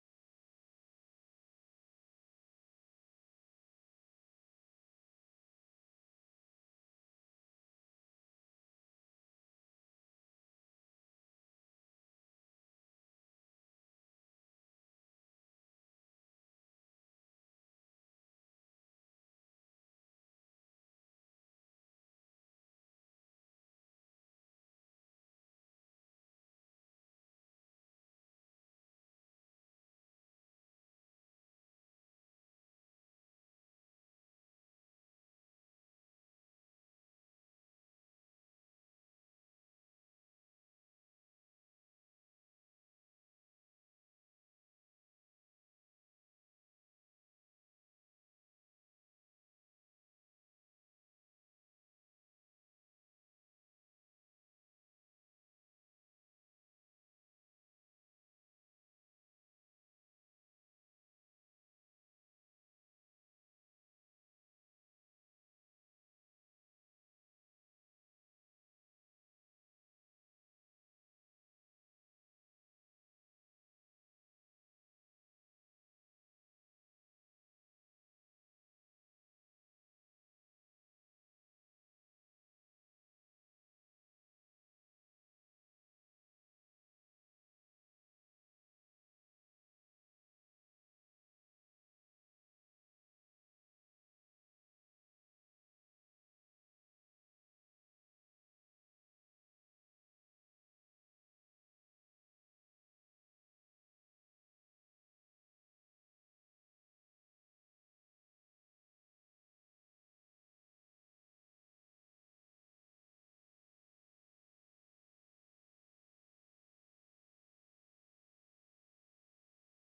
Praise and Worship on June 16th 2024